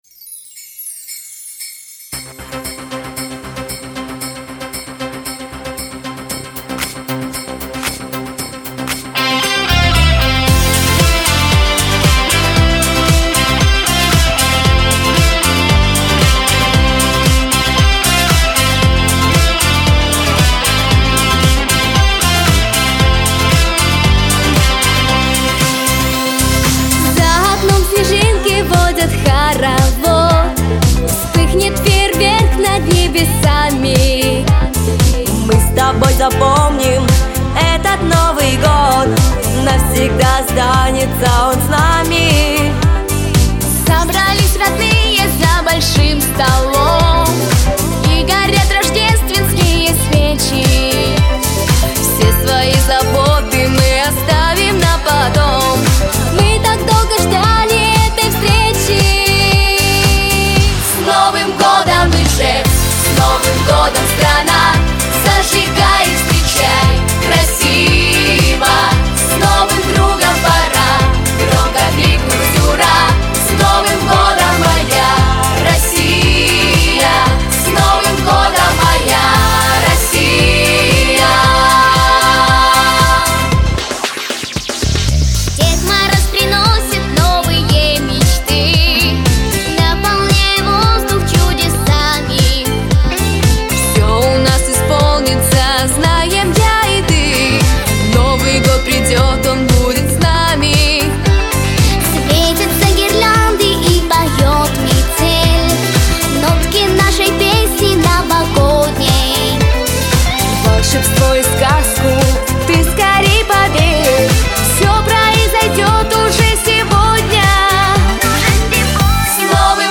• Качество: Хорошее
• Категория: Детские песни
🎵 минусовка
🎶 Детские песни / Песни на праздник / Песни на Новый год 🎄